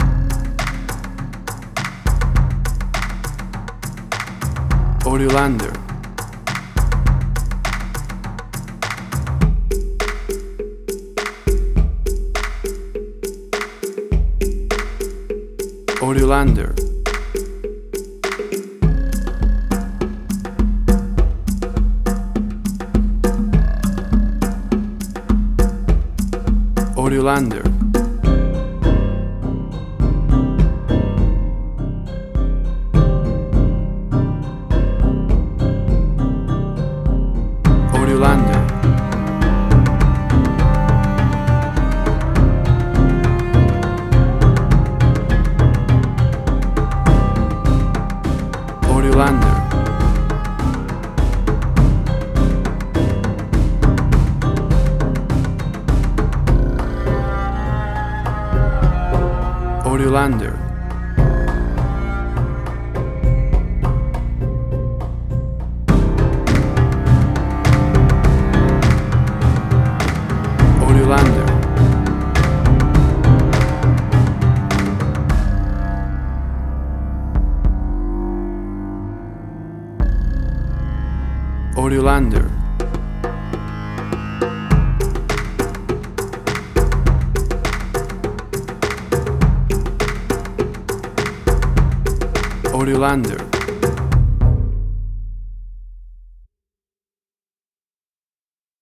Suspense, Drama, Quirky, Emotional.
Tempo (BPM): 102